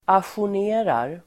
Uttal: [asjor_n'e:rar]